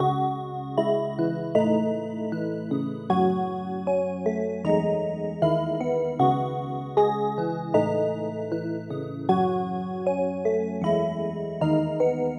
描述：155 BPM C小调 ElectraX Pad
Tag: 155 bpm Trap Loops Pad Loops 2.08 MB wav Key : Cm FL Studio